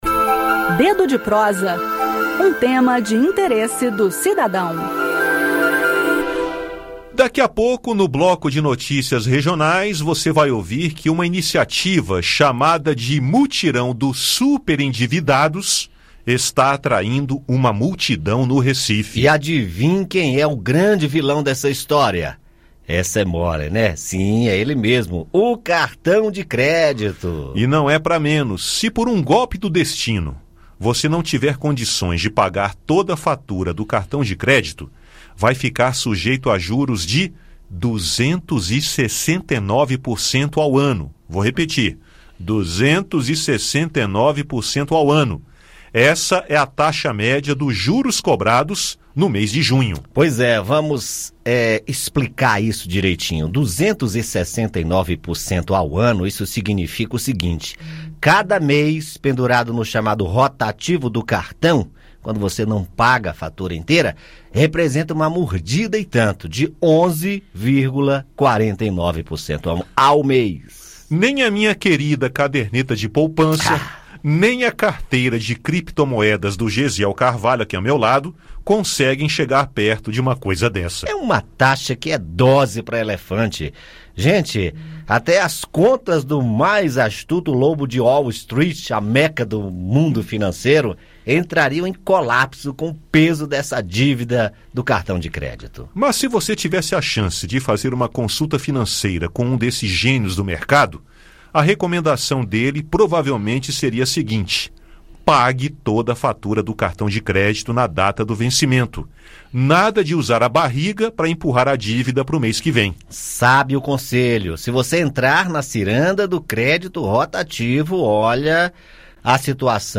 No "Dedo de Prosa" de hoje, quinta-feira (18), o assunto são os juros do cartão de crédito. Ouça o áudio com o bate-papo.